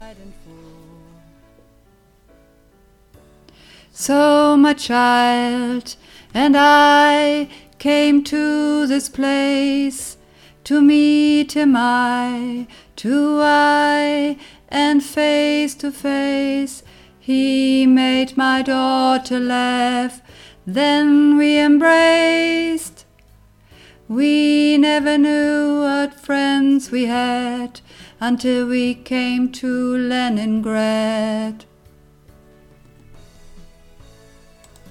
Leningrad__2_Sopran_und_Alt_Alt_Solo.mp3